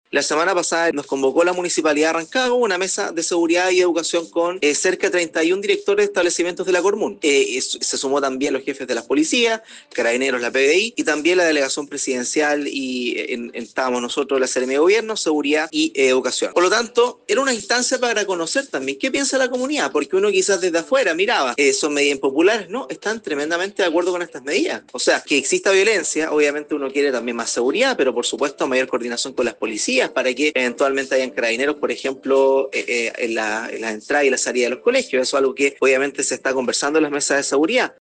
El nuevo Seremi de Gobierno en la región de O’Higgins, Matías Riveros señaló en entrevista con Manía que la situación de amenazas y hechos de presunta violencia en los colegios se está abordando con todas las autoridades para buscar una solución que nazca desde las propias comunidades, escuchemos: